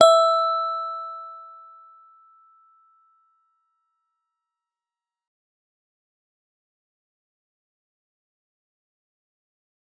G_Musicbox-E5-f.wav